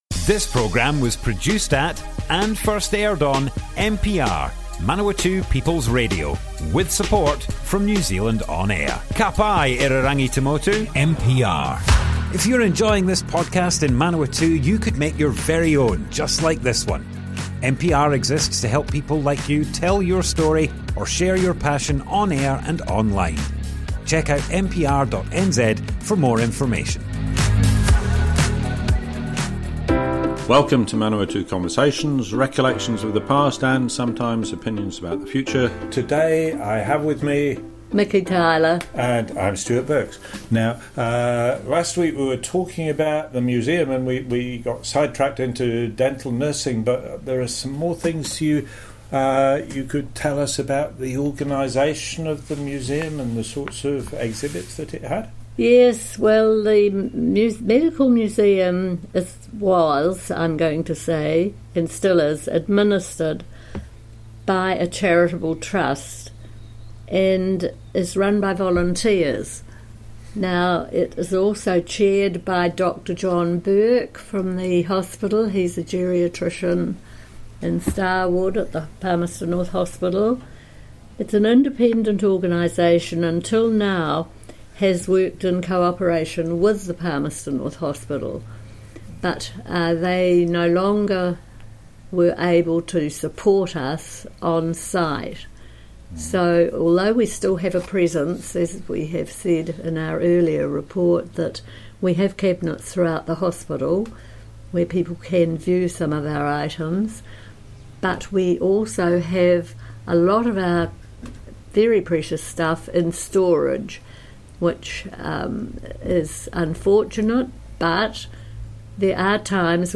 Manawatu Conversations More Info → Description Broadcast on Manawatu People's Radio, 10th December 2024.
oral history